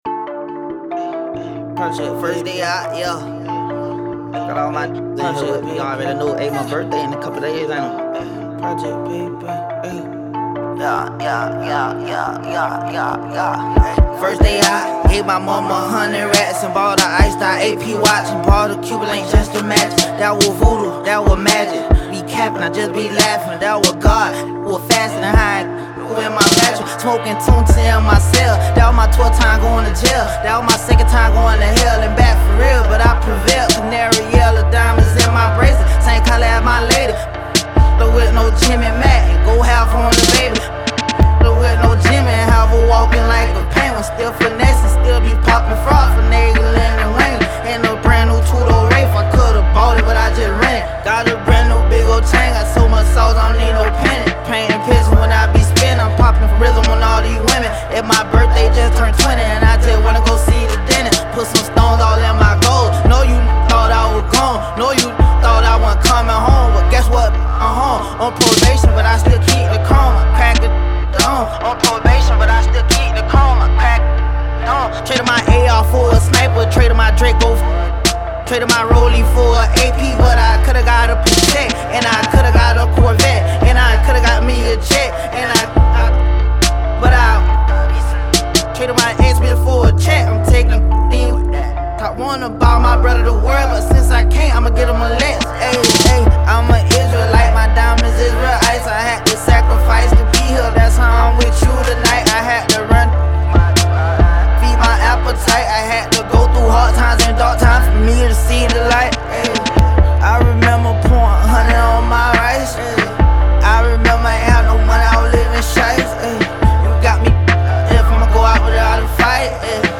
SINGLESHIP-HOP/RAP